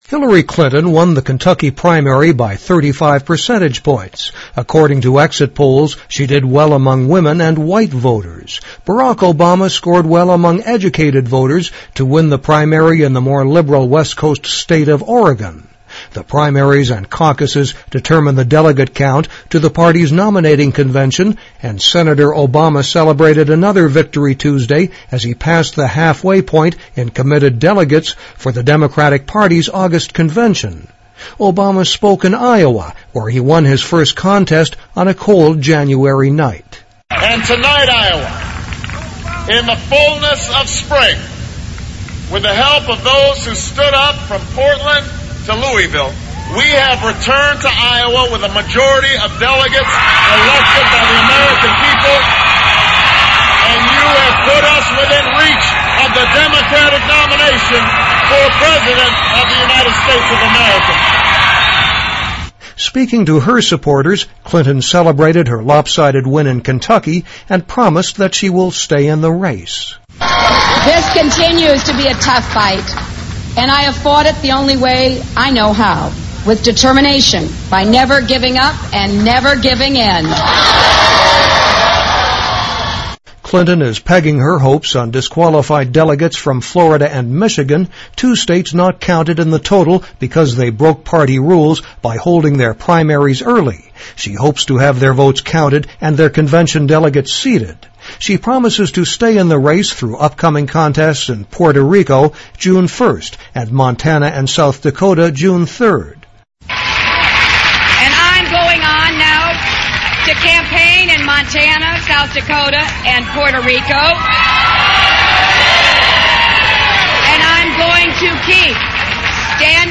位置：首页 > 英语听力 > 英语听力教程 > 英语新闻听力